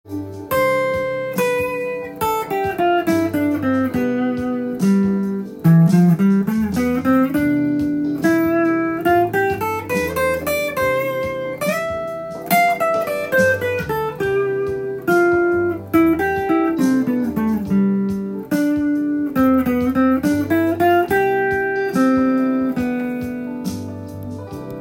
ここでは４分音符と３連符を混ぜてみました。